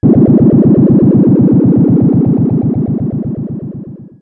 Machine06.wav